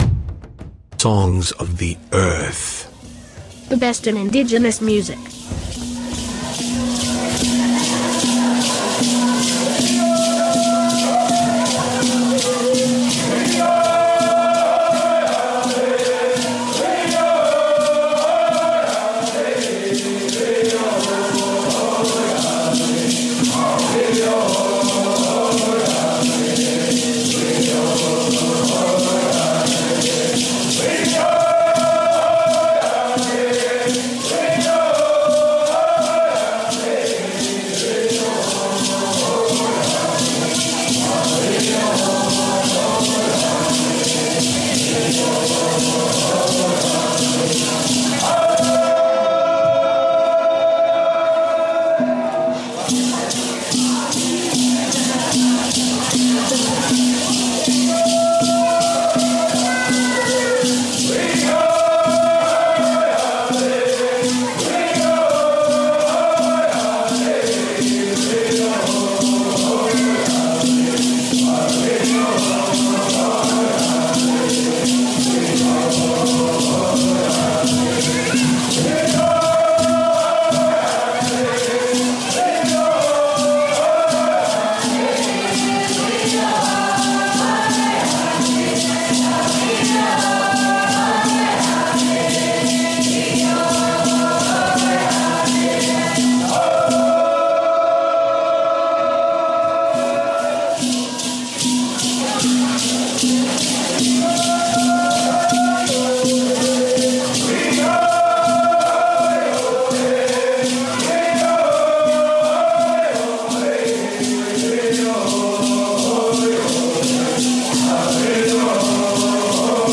Focussing on both traditional and contemporary musical genres, with a focus on Iroquois Social Dance music.
Music of Indigenous People (traditional/contemporary genres)